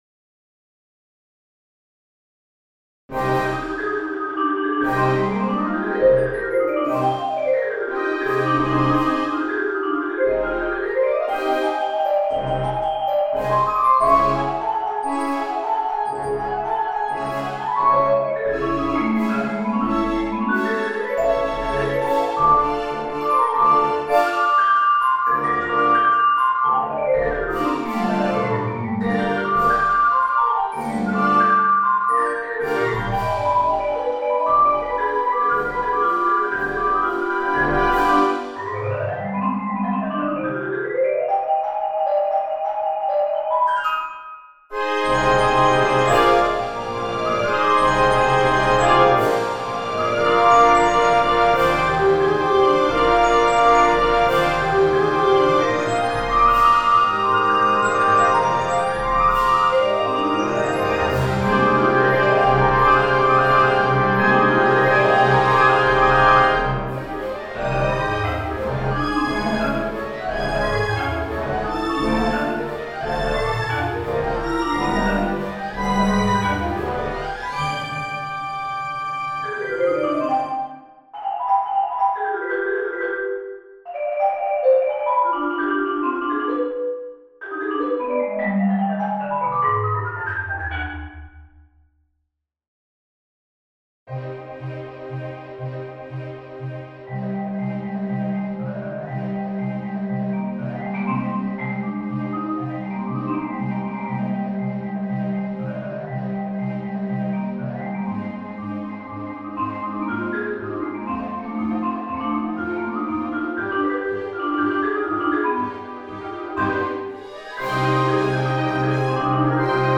Voicing: Solo / Ensemble w/ Band